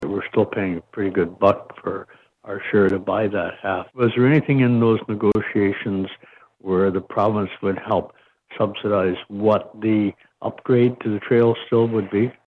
Centre Hastings Mayor Tom Deline raised the question at the meeting of Hastings County Council on Thursday.